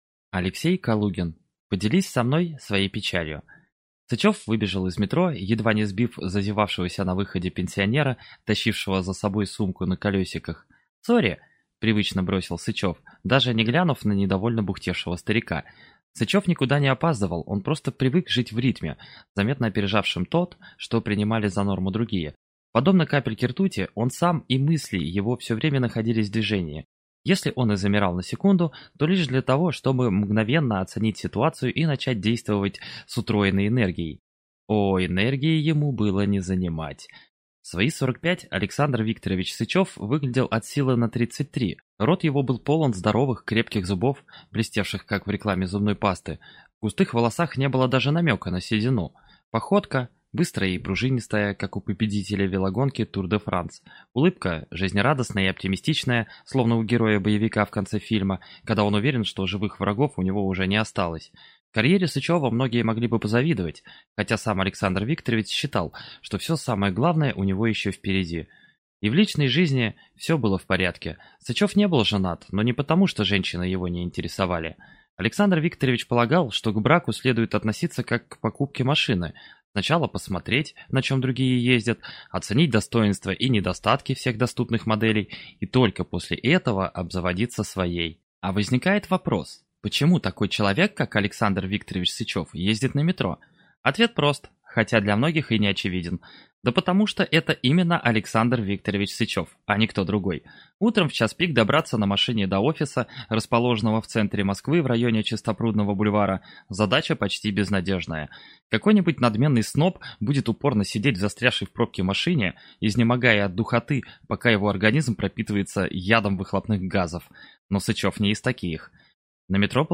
Аудиокнига Поделись со мной своей печалью | Библиотека аудиокниг